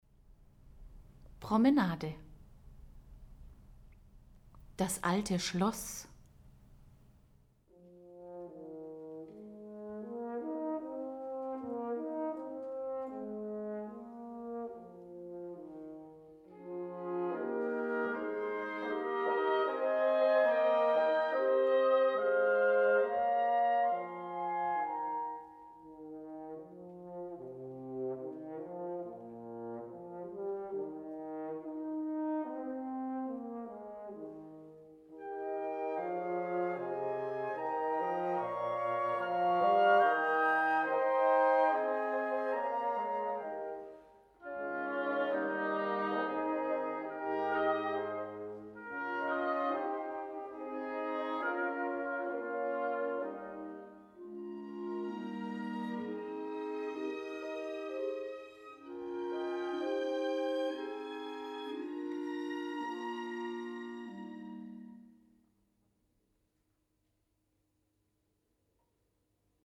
Hör dir die Musik „Bilder einer Ausstellung“ von Modest Mussorgsky, gespielt von der Philharmonie Salzburg an.